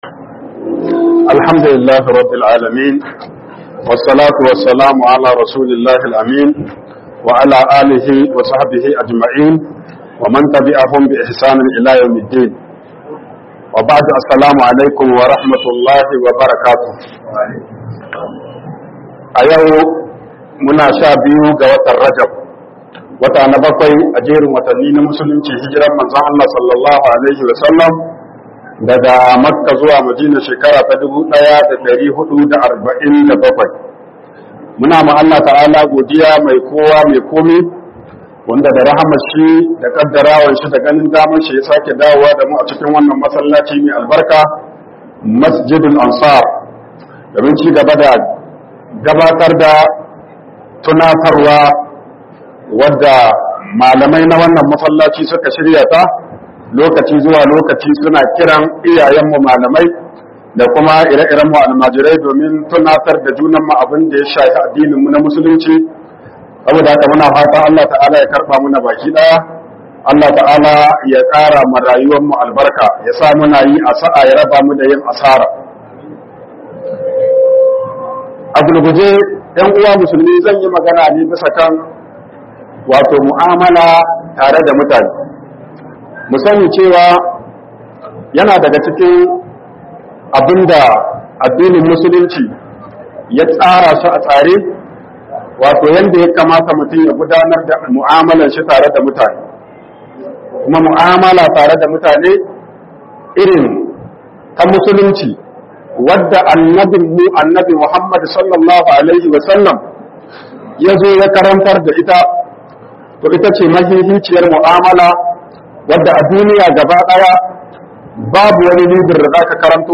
YA YA KAMATA KA MU'AMALANCI MUTANE - HUƊUBOBIN JUMA'A